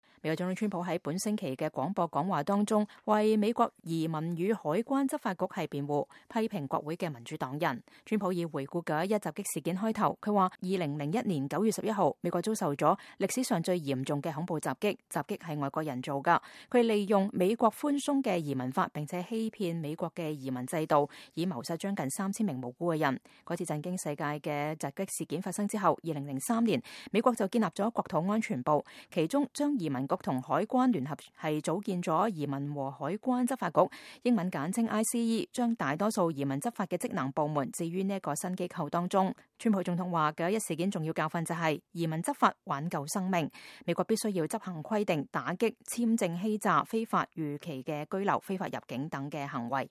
美國總統川普在本星期的廣播講話中為美國移民與海關執法局辯護，批評國會的民主黨人。幾名民主黨要員，包括來自紐約的吉利布蘭德參議員和來自麻薩諸塞州的沃倫參議員呼籲取消民與海關執法局。